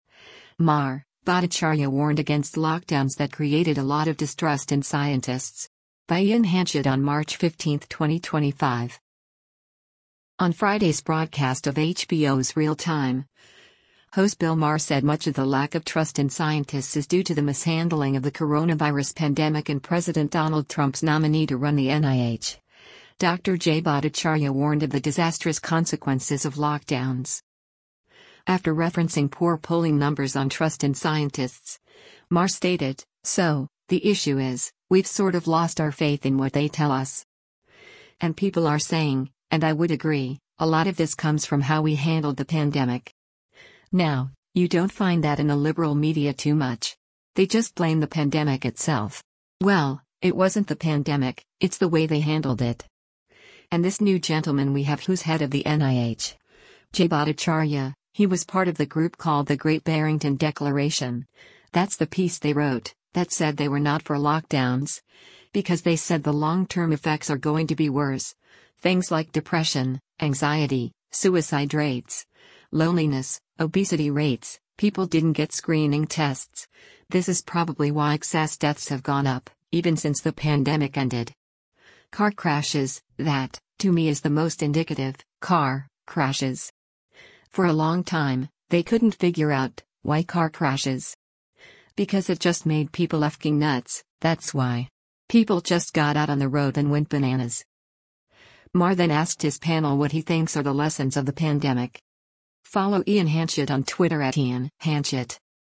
On Friday’s broadcast of HBO’s “Real Time,” host Bill Maher said much of the lack of trust in scientists is due to the mishandling of the coronavirus pandemic and President Donald Trump’s nominee to run the NIH, Dr. Jay Bhattacharya warned of the disastrous consequences of lockdowns.
Maher then asked his panel what he thinks are the lessons of the pandemic.